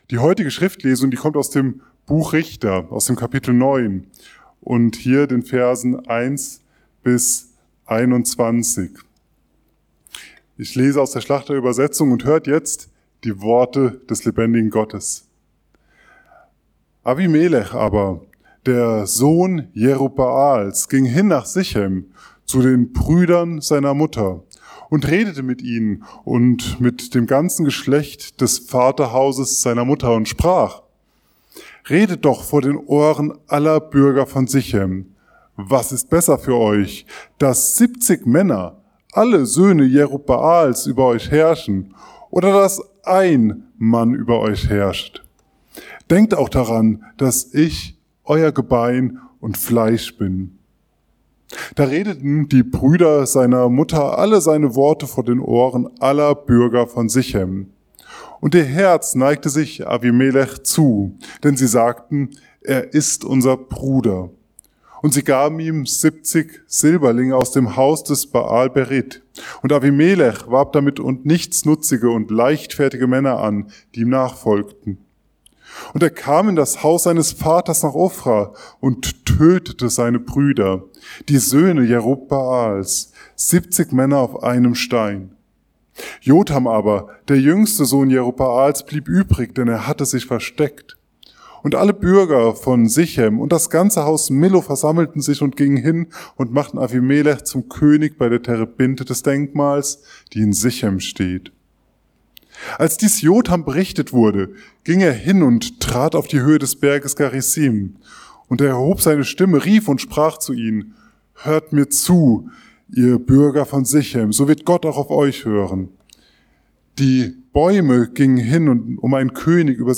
Richter 9,1-21 ~ Mittwochsgottesdienst Podcast